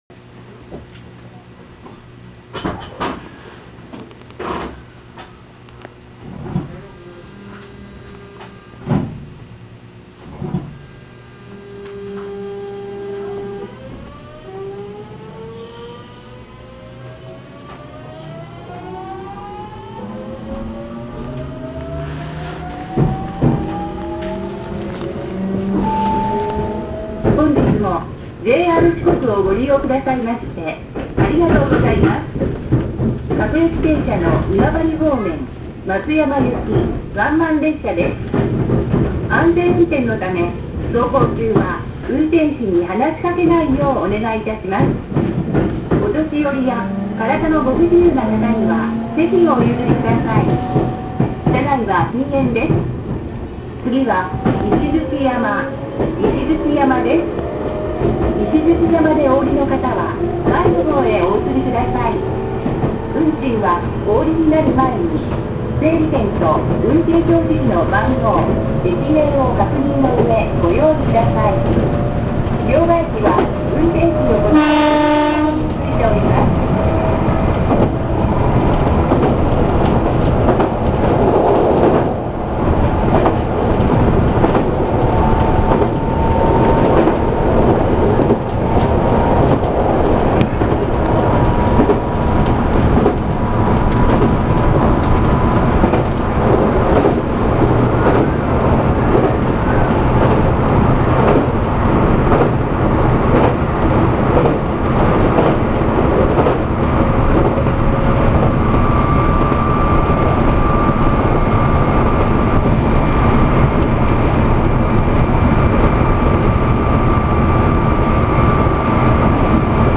〜車両の音〜
・7000系走行音
【予讃線】伊予西条→石鎚山（4分18秒：1.36MB）
日立GTOのかなり初期のものを用いており、現在は機器更新も徐々に進められています。尚、収録した列車は伊予西条始発だったため、発車の際にポイントを渡っており、フル加速するのは１分を過ぎてからになります。